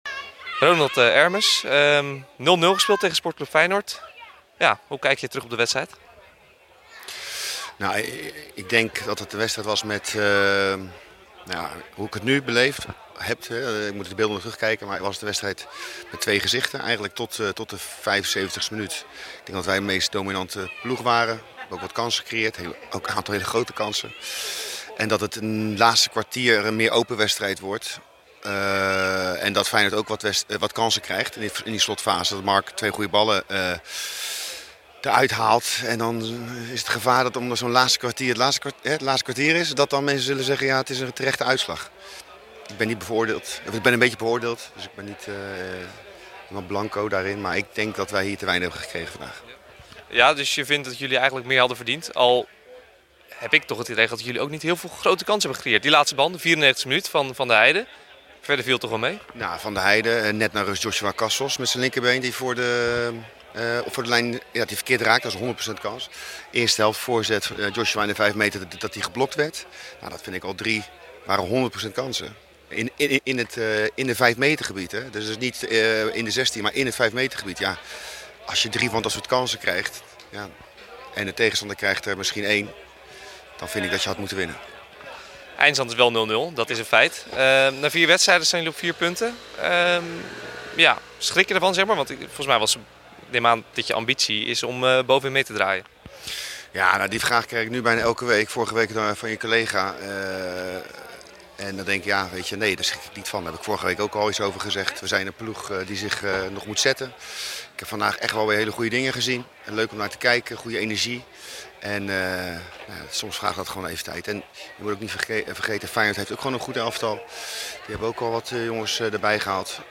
na gelijkspel tegen sc Feyenoord